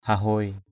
/hah-hʊoɪ/ (t.) rộng rãi = généreux, qui a le cœur sur la main. widely. daok deng hahuai saong yut-cuai _d<K d$ h=h& _s” y~T-=c& ăn ở rộng rãi với bạn...